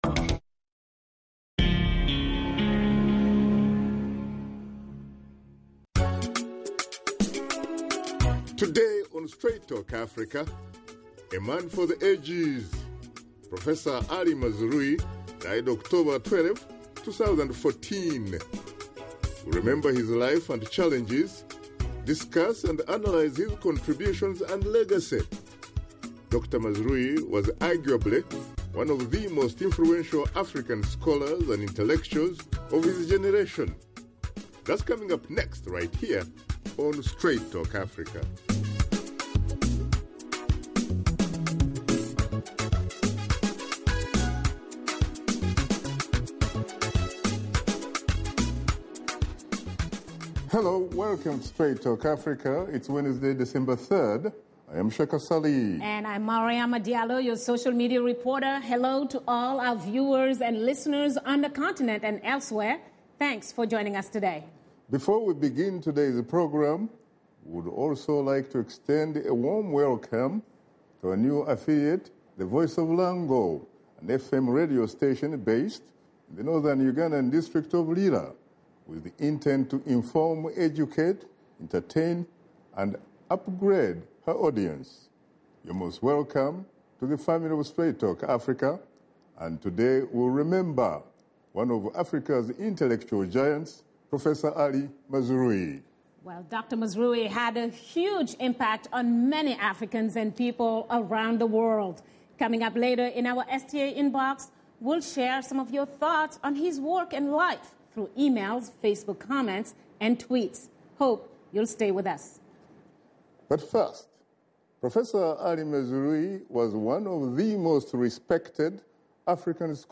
Host Shaka Ssali and his guests remember the life, contributions and legacy of Professor Ali Mazrui.